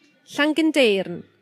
Summary Description Llangyndeyrn.ogg Cymraeg: Ynganiad o enw'r pentref yn y dafodiaith leol.